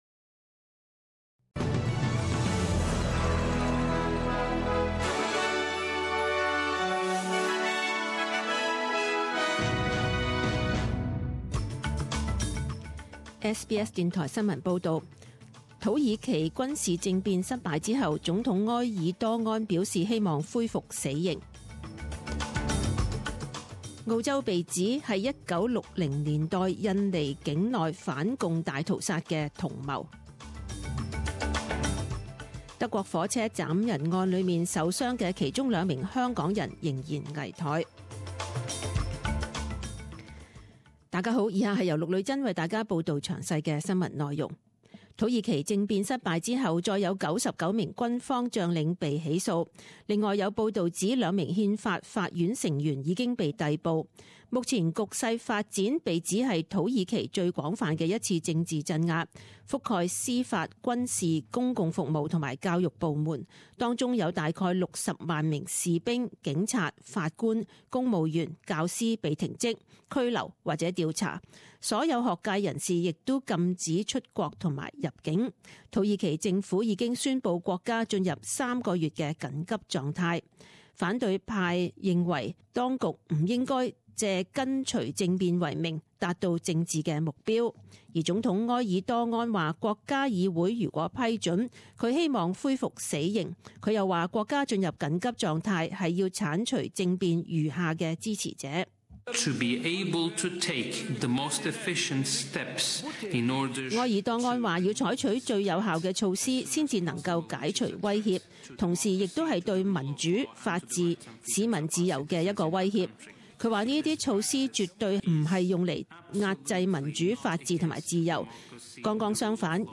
十点钟新闻报导（七月二十一日）